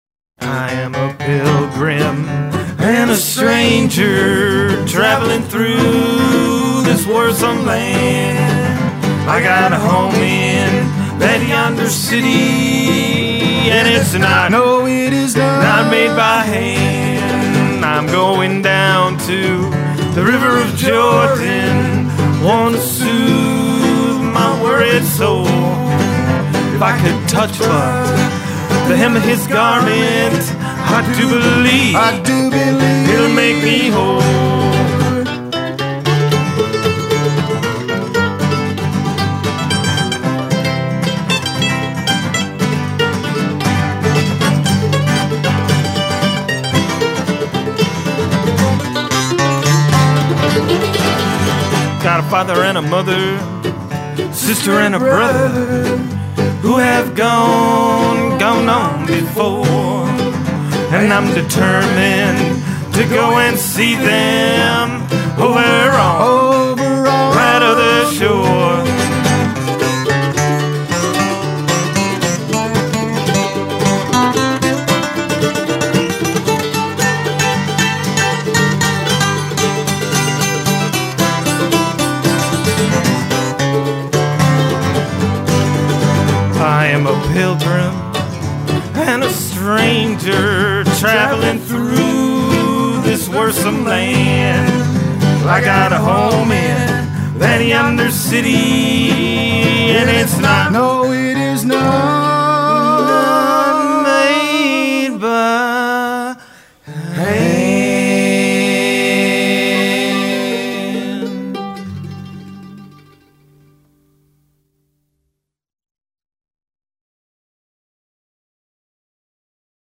Their broad repertoire blends the sounds of bluegrass and country music with the new acoustic sound of today and powerful vocal harmonies to give them unique depth.